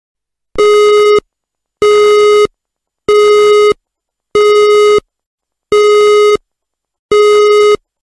Beep
Beep x 6
Beep.mp3